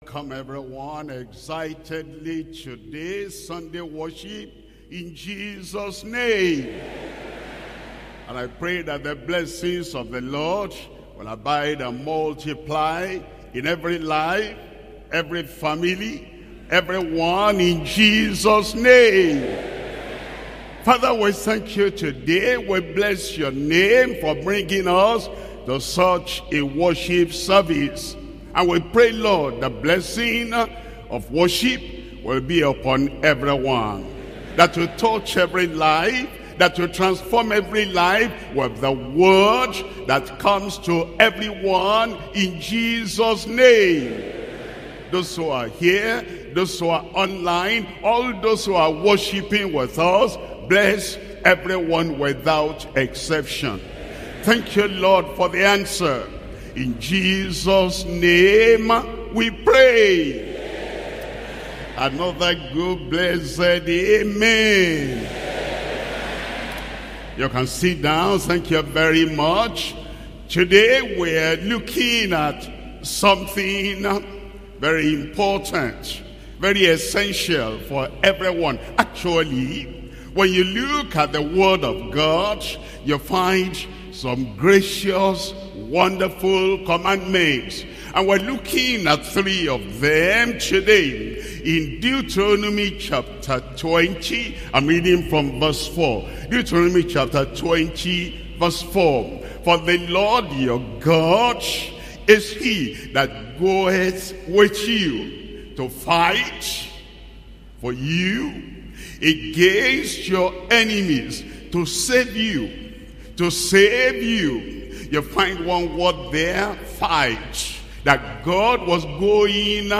2024 Sunday Worship Service